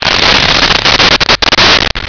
Sfx Flame Burst 01
sfx_flame_burst_01.wav